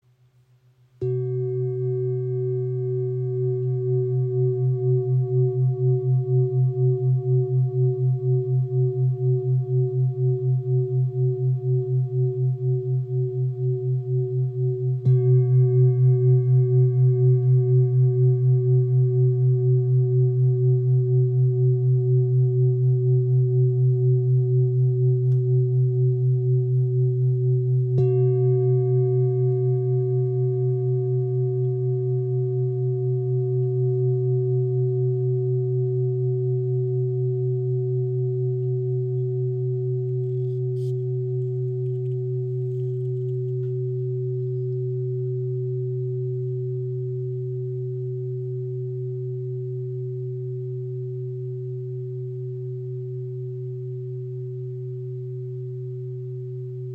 Tibetische Klangschale 25.5 cm – klarer Ton C aus Nepal • Raven Spirit
Handgefertigte Klangschale mit Blume des Lebens und Muschelhorn. Klarer, tiefer Ton C – ideal für Klangarbeit, Meditation und Entspannung.
Klangbeispiel
Ihr obertonreicher Klang im Ton C ist klar und erdend.